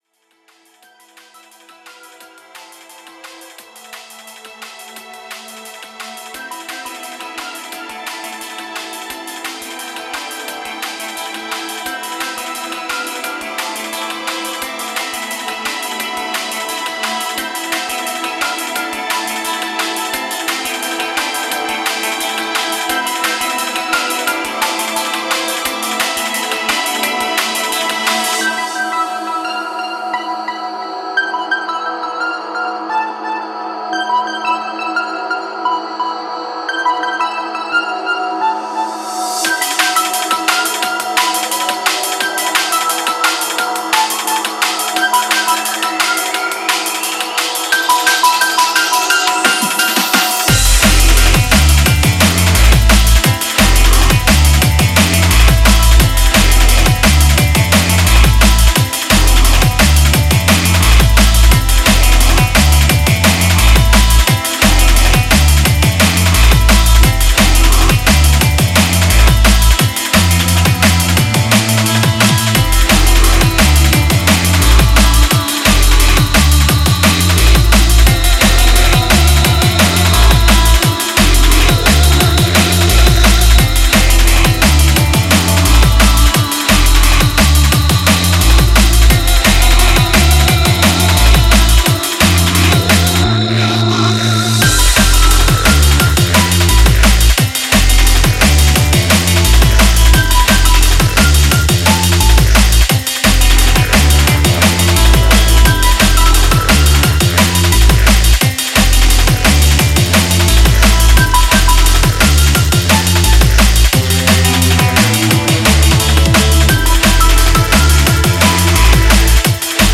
drum and bass duó